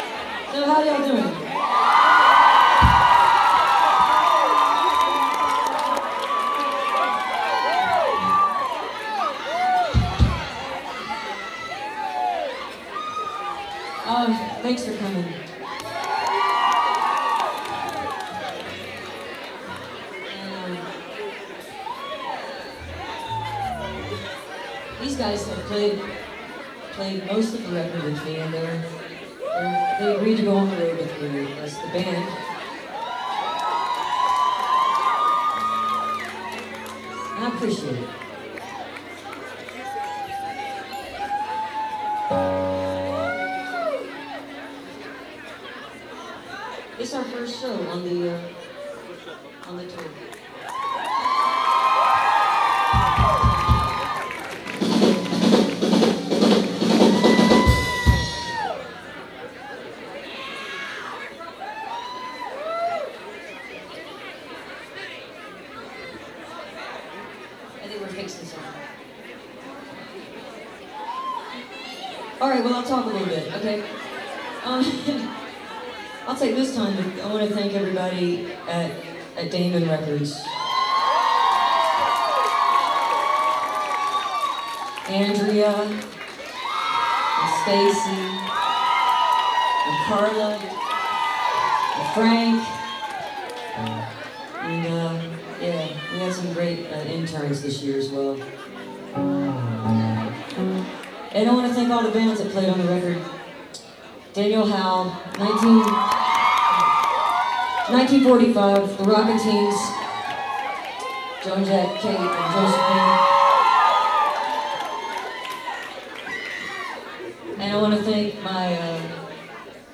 lifeblood: bootlegs: 2001-03-29: the echo lounge - atlanta, georgia (amy ray and the butchies)
02. talking with the crowd (2:17)